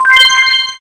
match-confirm.ogg